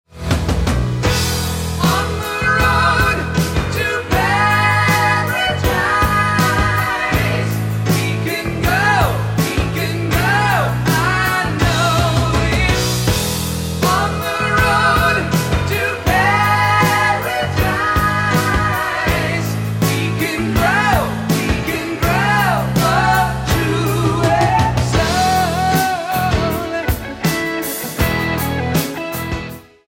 guitar, drums, piano, synthesizer, vibes, vocals
bass
Album Notes: Recorded at Mushroom Studios, Vancouver, Canada